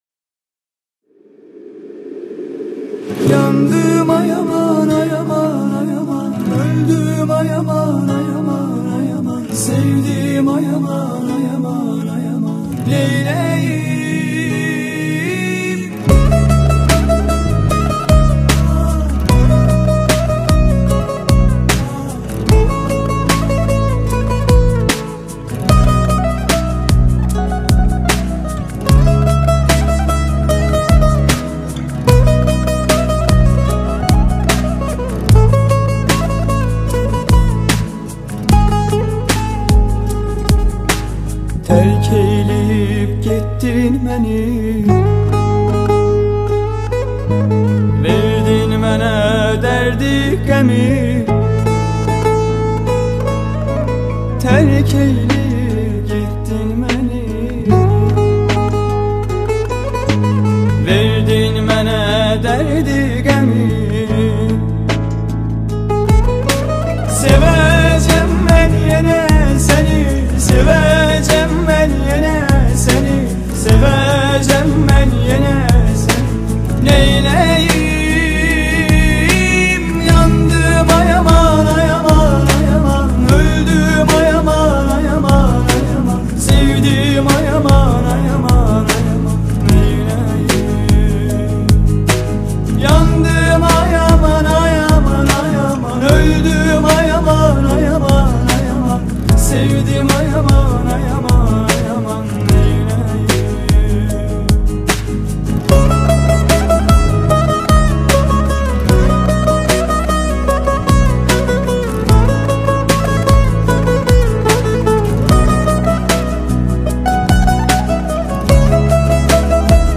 это эмоциональная песня в жанре турецкой поп-музыки